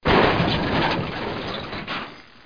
1 channel
00213_Sound_Bash.mp3